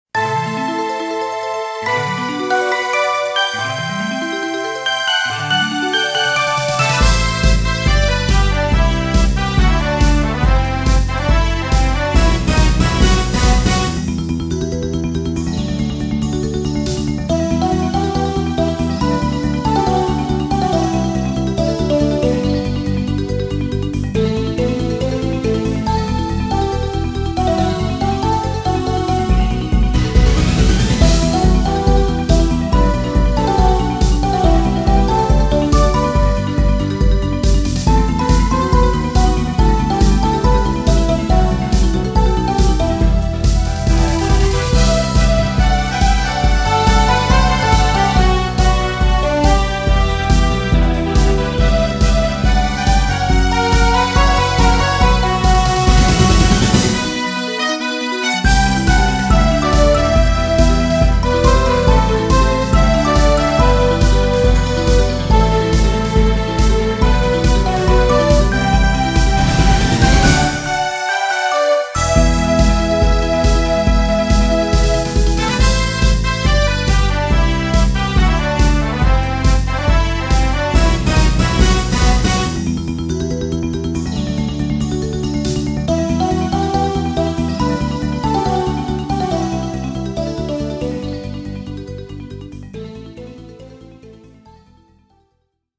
これらの曲は、全てドリームキャストを使って作りました。
パソコンのMIDIではありませーん。